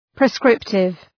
Προφορά
{prı’skrıptıv}
prescriptive.mp3